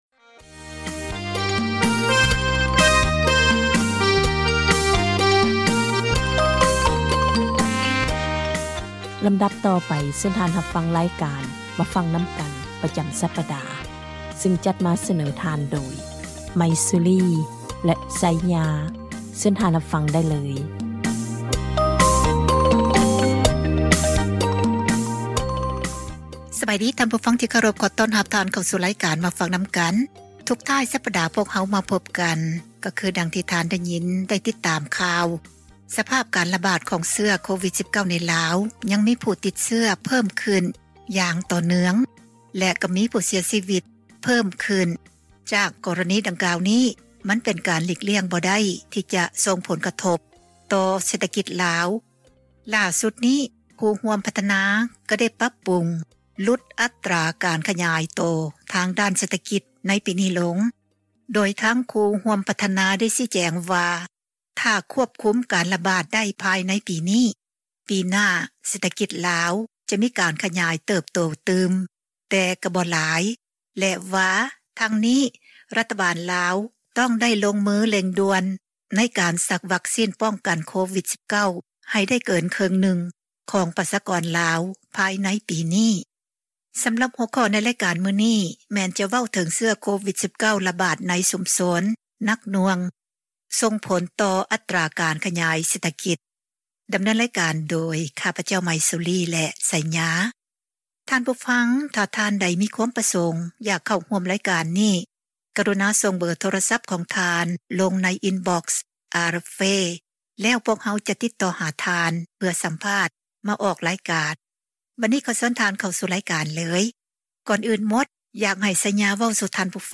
ການສົນທະນາ